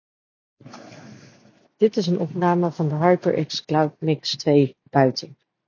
In de volgende opnames is goed te horen dat stemgeluid binnen beter tot zijn recht komt dan in de buitenopname. In beide opnames is het stemgeluid wel zeer goed te verstaan.
HyperX-cloud-mix2-buiten.mp3